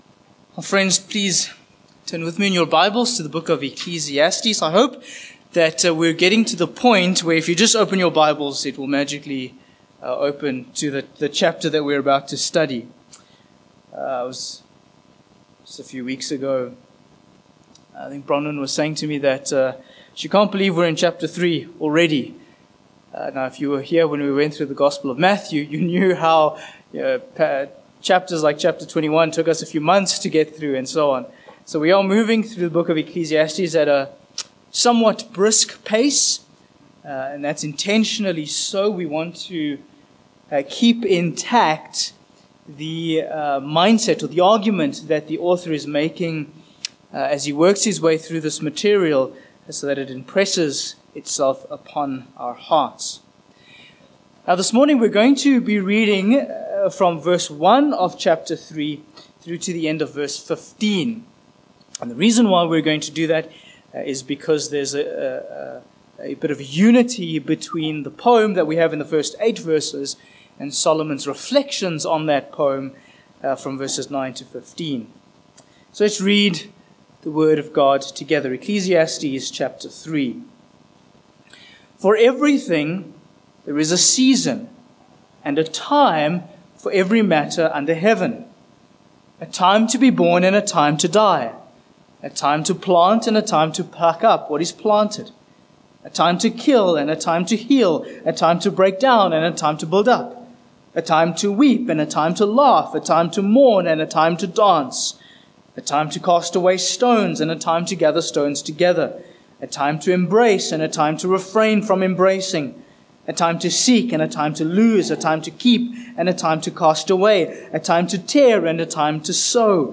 Ecclesiastes 3:9-15 Service Type: Morning Passage